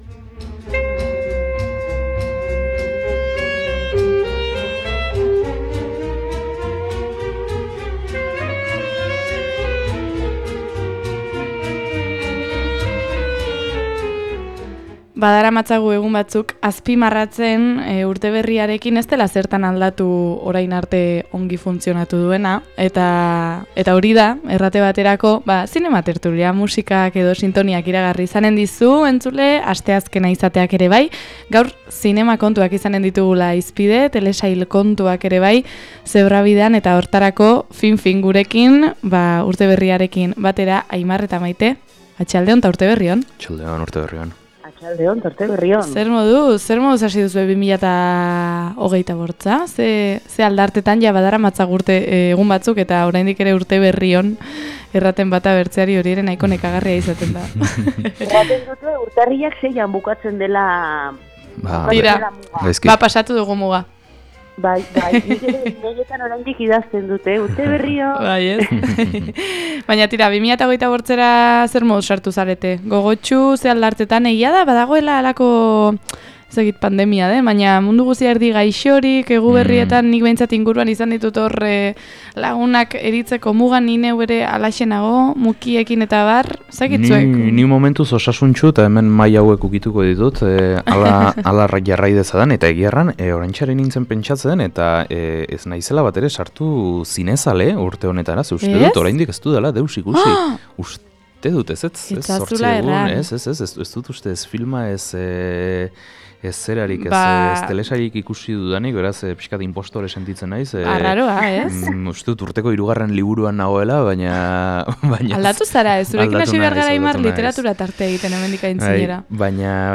2025a: zinegelak okupatzeko urtea · Zinema tertulia 01.08